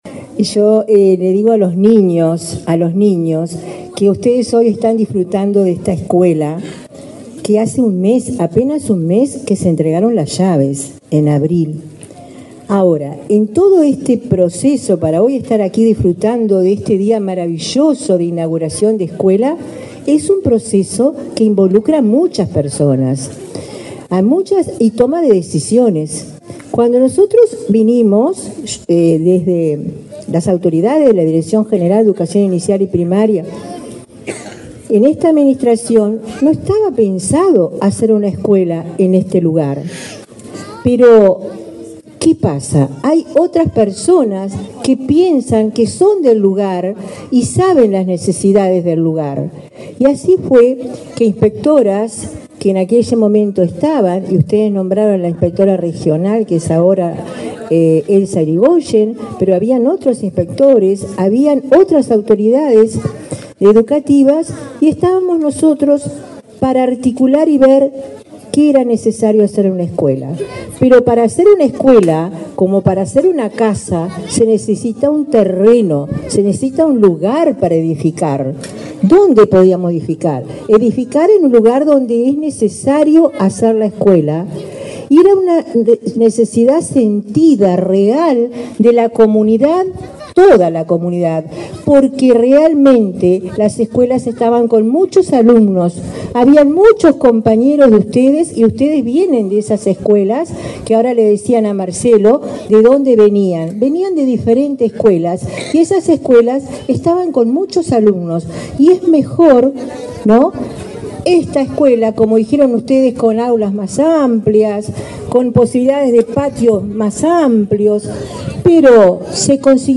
Palabras de autoridades de la ANEP
Palabras de autoridades de la ANEP 18/10/2024 Compartir Facebook X Copiar enlace WhatsApp LinkedIn La directora general de Educación Inicial y Primaria, Olga de las Heras, y la presidenta de la Administración Nacional de Educación Pública (ANEP), Virginia Cáceres, participaron en el acto de inauguración de la escuela n.° 321, en la zona de Pinepark, en la Costa de Oro de Canelones.